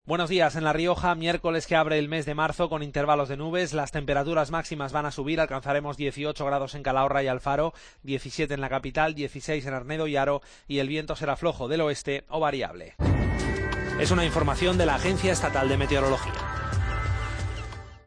AUDIO: Pronóstico. Agencia Estatal de Meteorología.